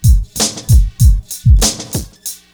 BEAT 1 95 00.wav